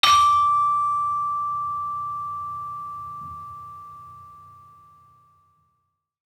HSS-Gamelan-1
Saron-4-D5-1.wav